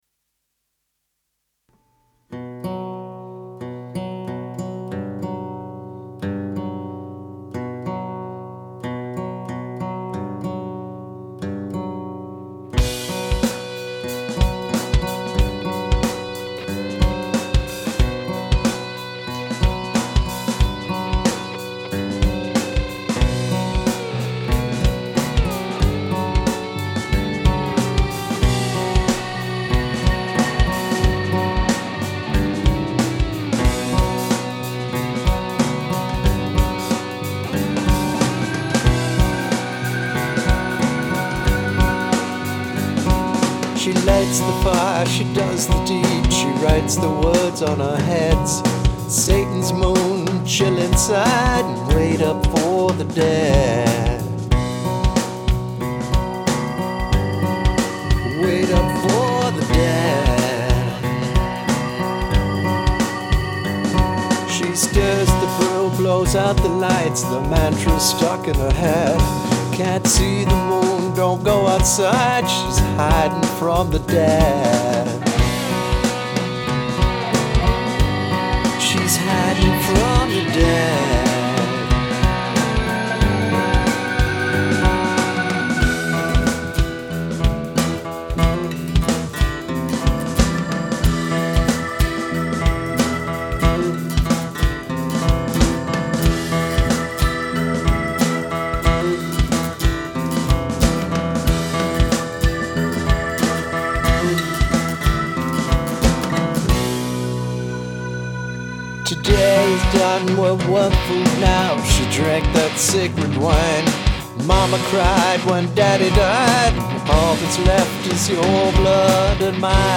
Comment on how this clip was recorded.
I've tried a technique of not bringing the note 100% in key and it sounds better = to me. But the sibilance bothers me, maybe I've done a song with too many SSS's in it. I did a better recording job than usual and was able to get by with minimal eq and super-minimal compression.